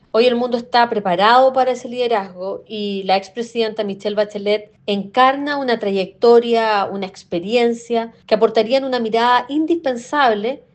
En conversación con Radio Bío Bío, la representante permanente de Chile ante Naciones Unidas, Paula Narváez, aseguró que el organismo enfrenta una brecha histórica al no haber sido nunca liderado por una mujer, algo que, dijo, ya no se condice con los valores que promueve la ONU.